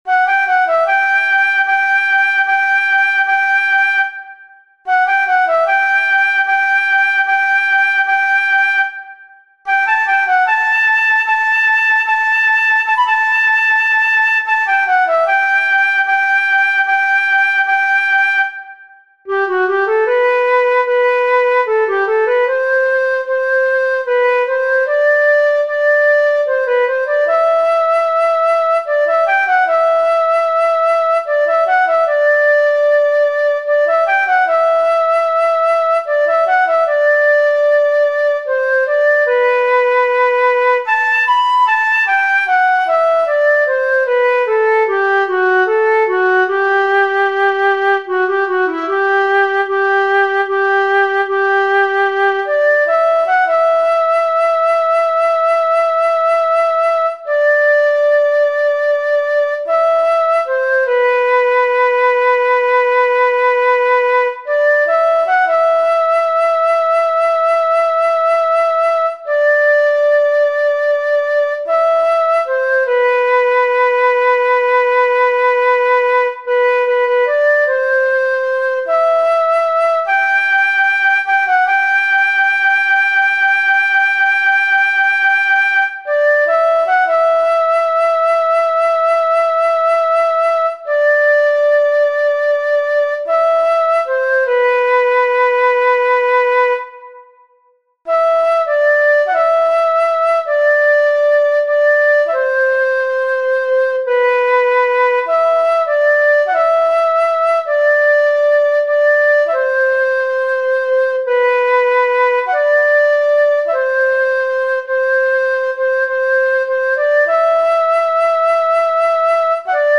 سطح : متوسط